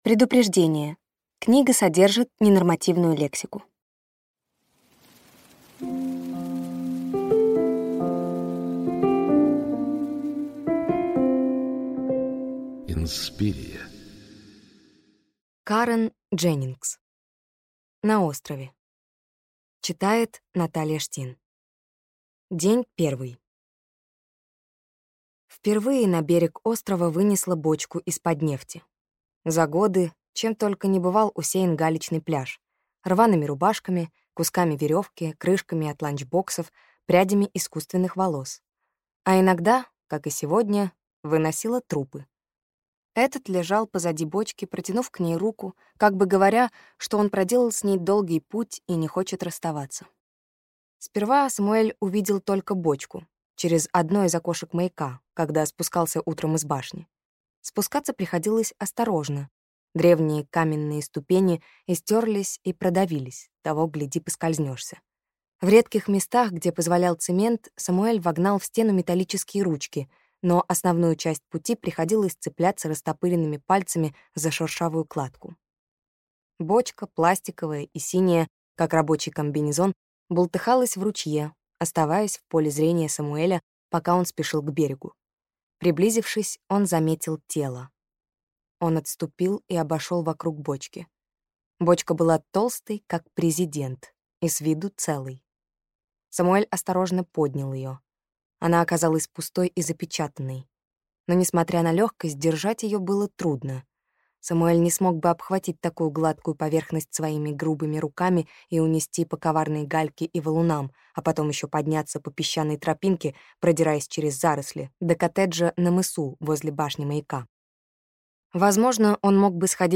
Аудиокнига На острове | Библиотека аудиокниг